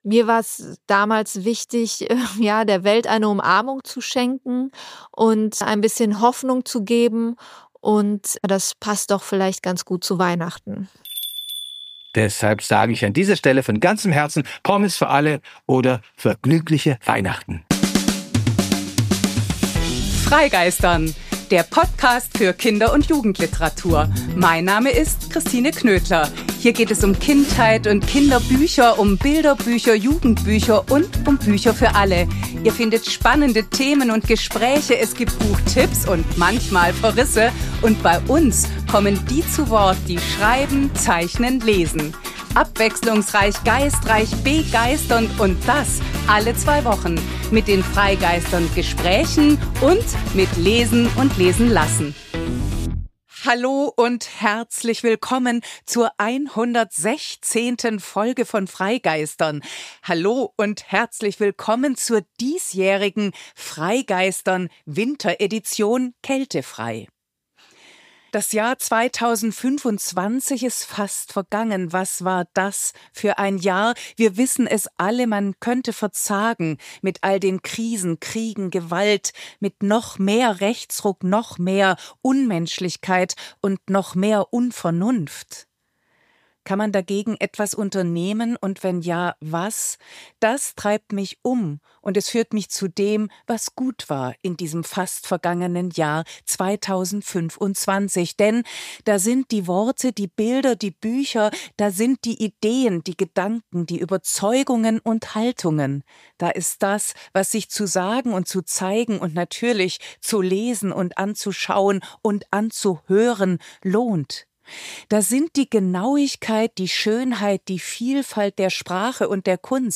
Beschreibung vor 4 Monaten Die 116. und zugleich letzte freigeistern!-Folge im fast vergangenen Jahr 2025 ist ein neues „kältefrei“: 19 Autor:innen und Illustrator:innen stellen 23 Bücher vor – und zwar ihre eigenen.